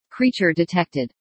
Creature_Detected.ogg